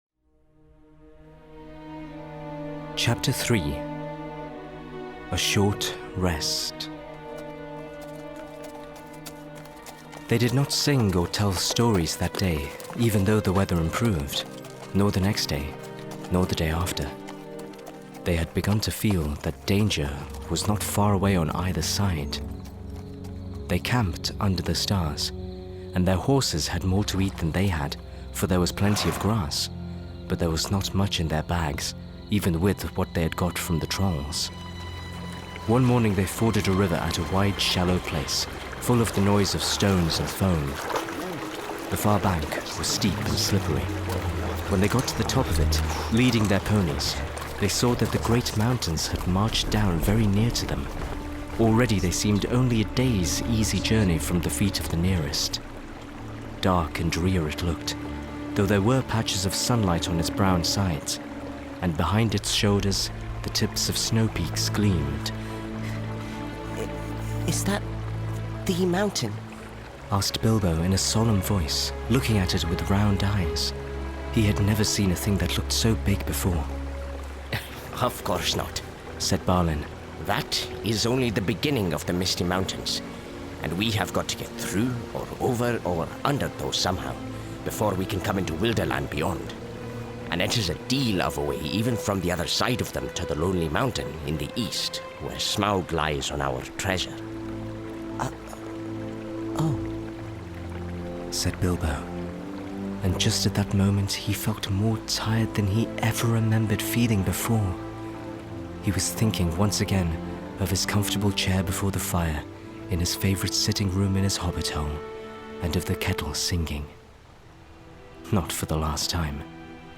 Lord Of The Rings And Hobbit Audiobooks (J.R.R. Tolkien)!